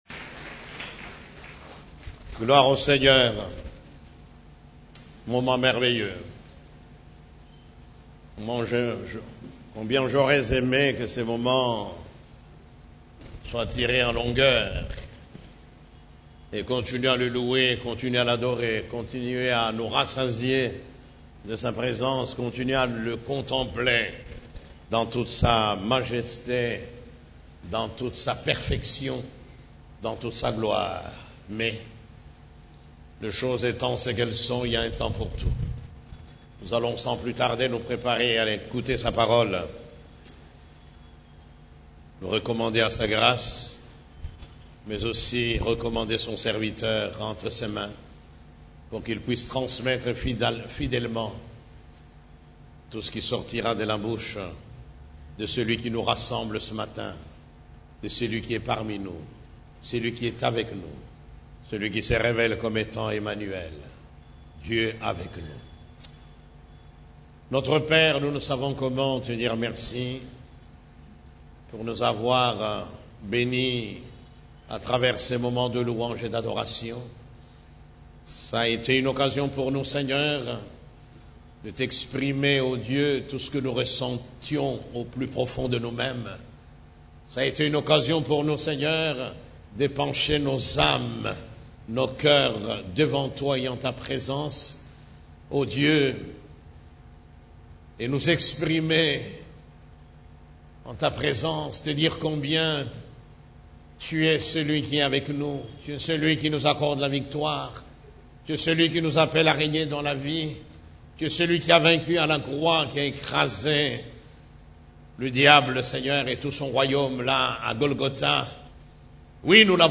CEF la Borne, Culte du Dimanche, L'Église de Jésus-Christ et sa révélation 2